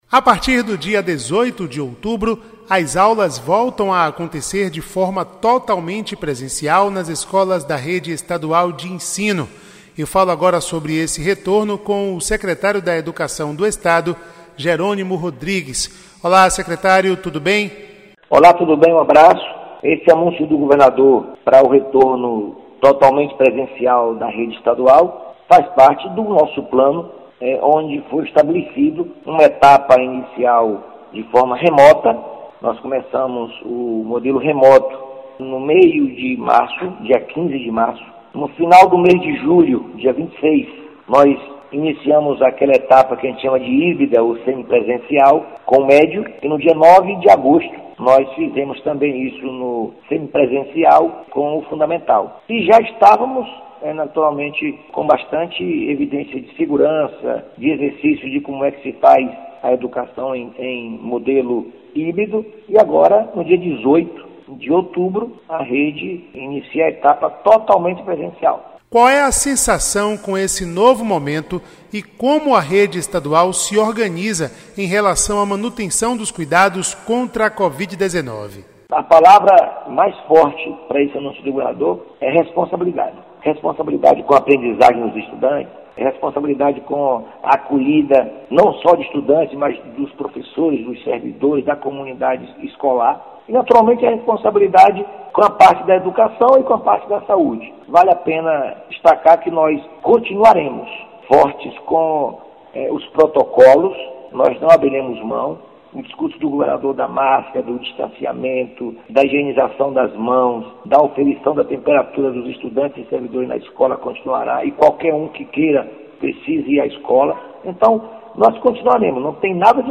Áudio da entrevista com o secretário da Educação do Estado, Jeronimo Rodrigues
ENTREVISTA_JERONIMO-RODRIGUES_SEC.mp3